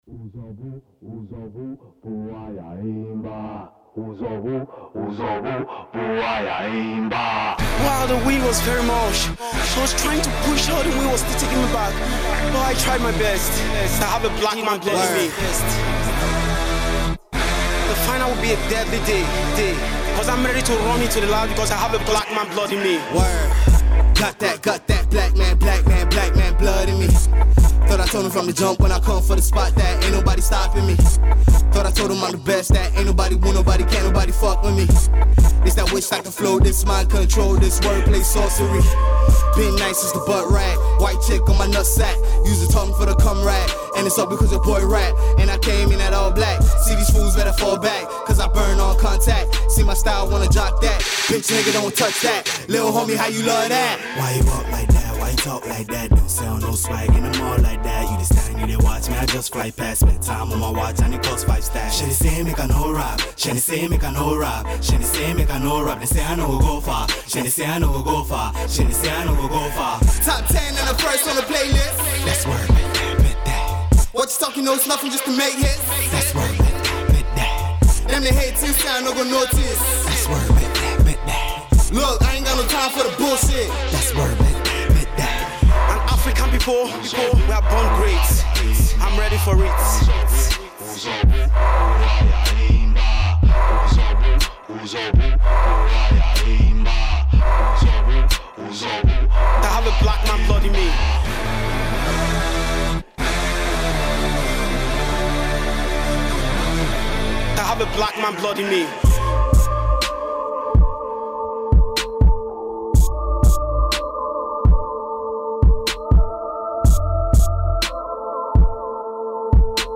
Hip-Hop
Freestyle